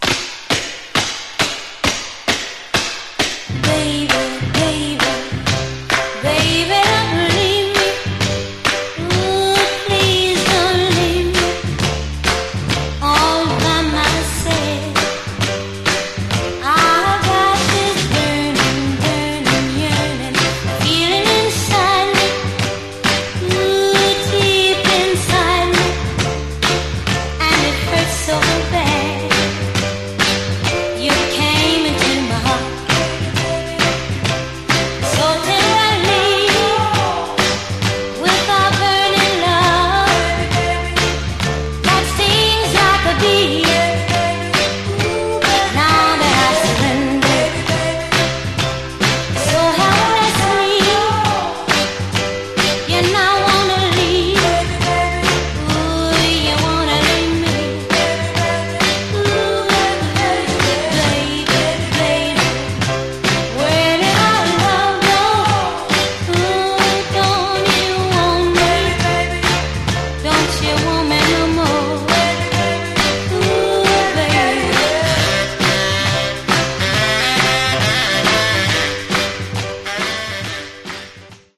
jaw-dropping audio